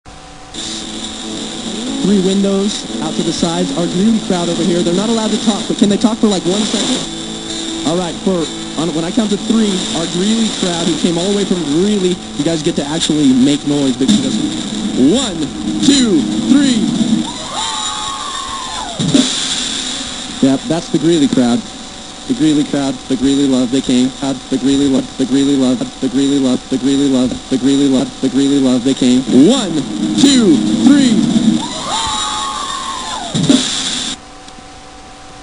live show audienceness!